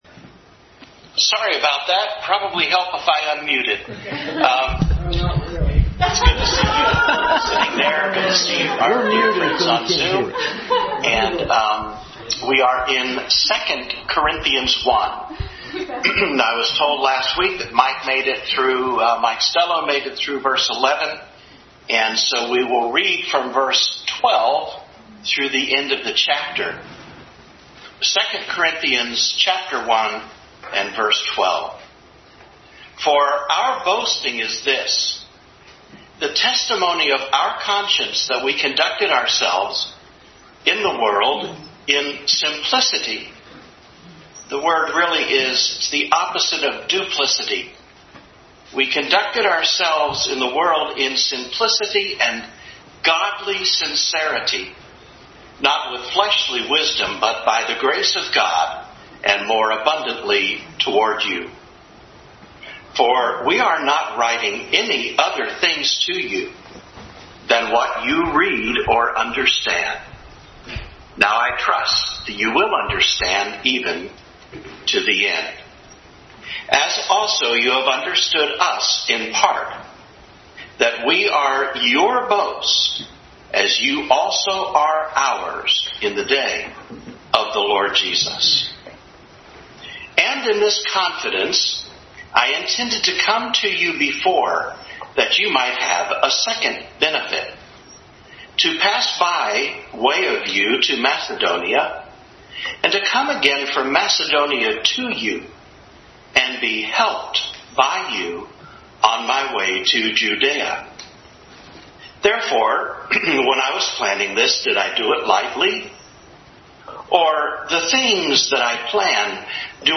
Adult Sunday School class continued study in 2 Corinthians.